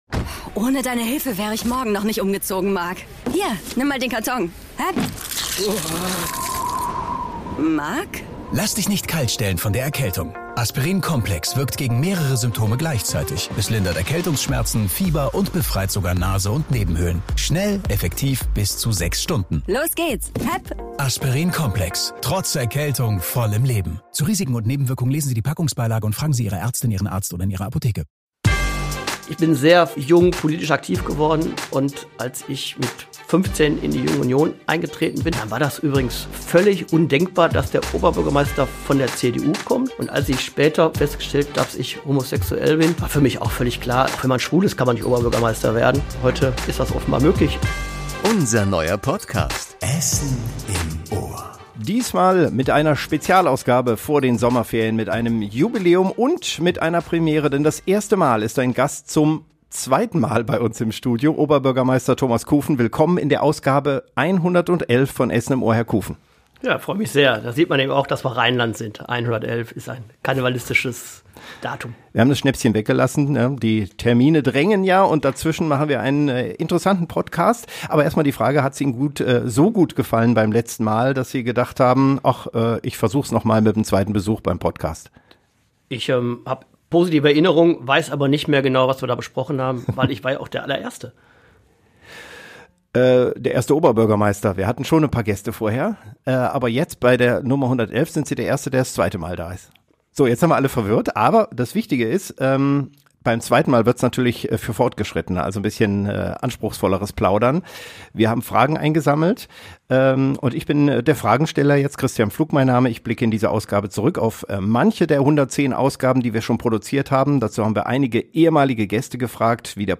Diesmal muss sich Oberbürgermeister Thomas Kufen einer Menge gemixter Fragen stellen, die Ihr im Vorfeld an uns geschickt habt.